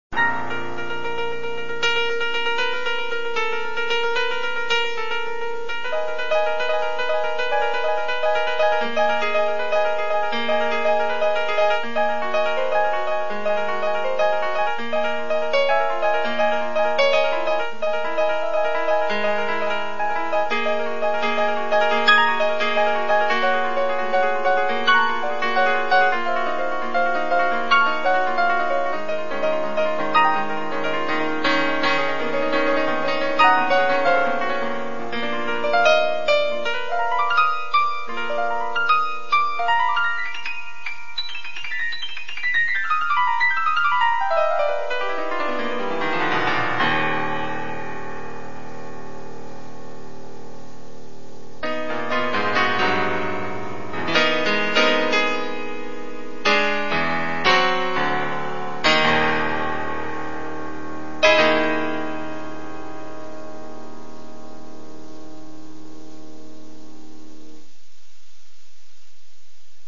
musikalische Untermalung am Klavier